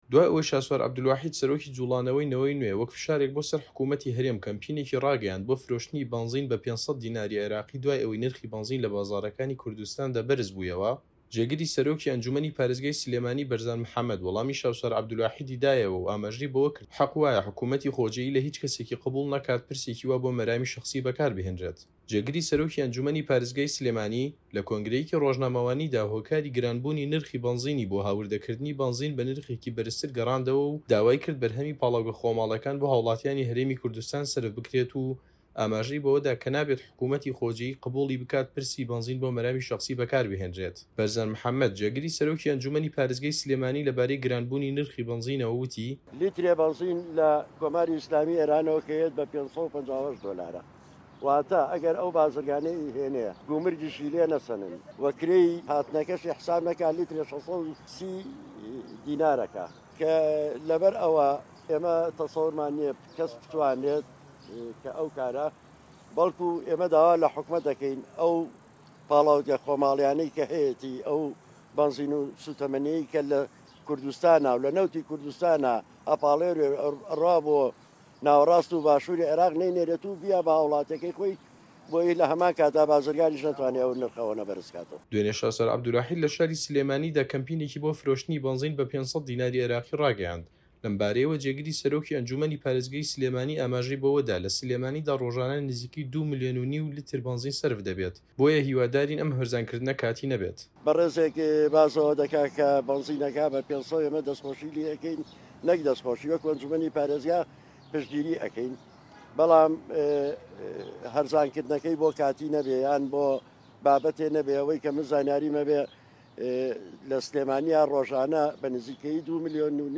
جێگری سەرۆکی ئەنجوومەنی پارێزگای سلێمانی لە کۆنگرەیەکی ڕۆژنامەوانیدا هۆکاری گرانبوونی نرخی بەنزینی بۆ هاوردەکردنی بەنزین بە نرخێکی بەرزتر لە جاران گەڕاندەوە و داوای کرد بەرهەمی پاڵاوگە خۆماڵییەکان بۆ هاوڵاتیانی هەرێمی کوردستان سەرفبکرێت و ئاماژەی بەوە دا کە نابێت حکومەتی خۆجێیی قبووڵی بکات پرسی...